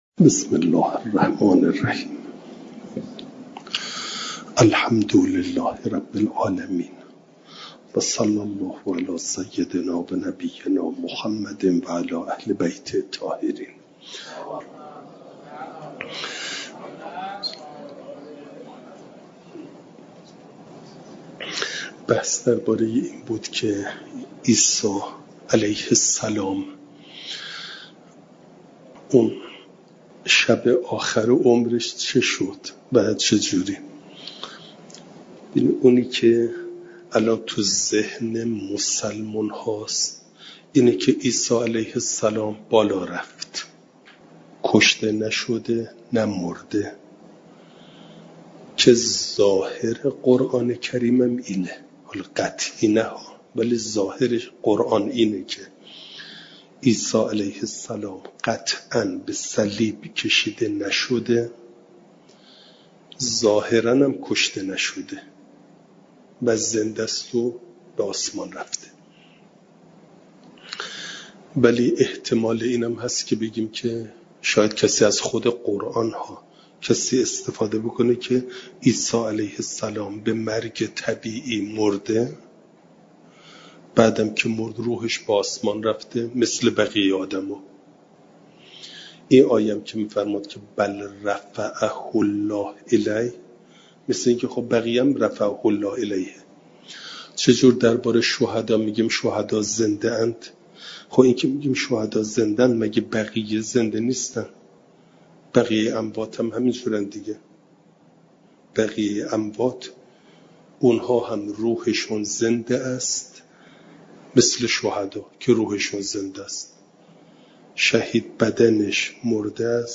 جلسه چهارصد و چهارم درس تفسیر مجمع البیان